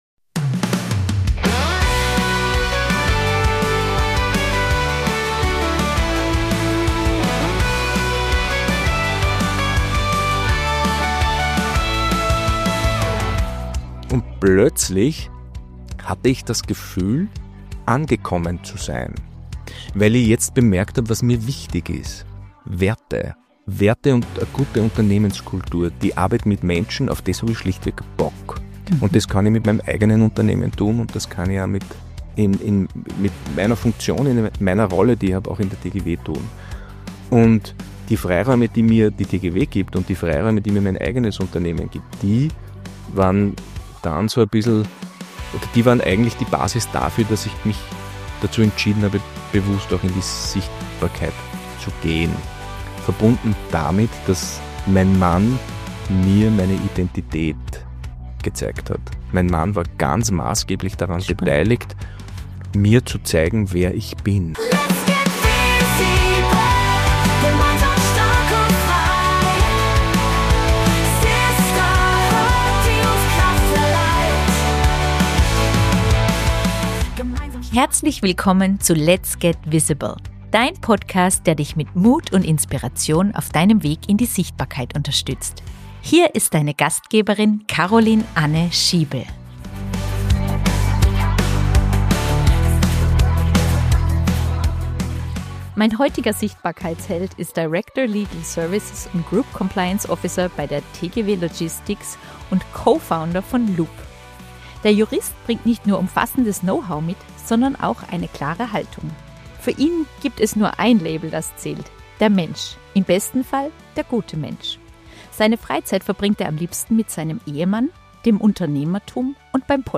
Ein Gespräch über Mut, Authentizität, den Umgang mit Neid und Ablehnung – und darüber, warum Sichtbarkeit ein entscheidender Faktor für persönlichen und beruflichen Erfolg ist. Diese Folge richtet sich an alle, die sich beruflich oder privat sichtbarer machen wollen – egal ob im Unternehmen oder als Gründer*in.